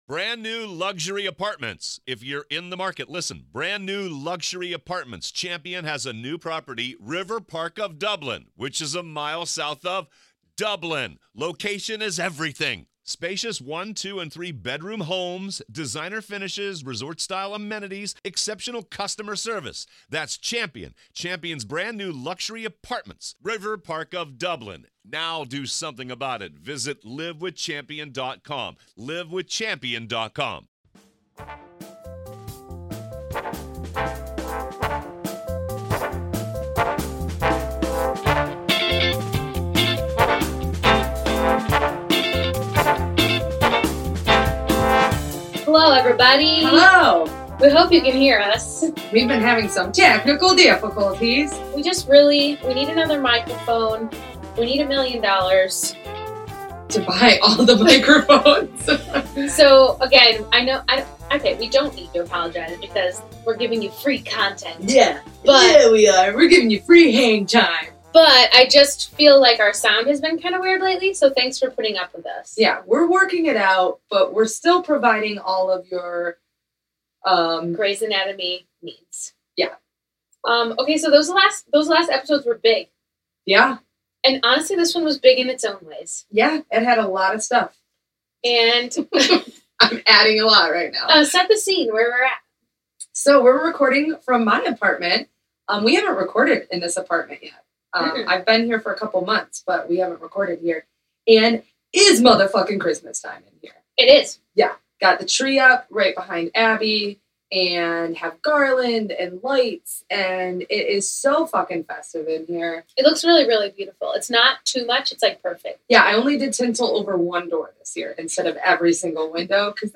We’re headed into the holiday swing, recording around Christmas Trees and reflecting on the past weekend.
The ladies are still struggling with sound, they need a new microphone, so they apologize, but you should all donate to them damnit!